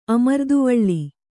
♪ amarduvaḷḷi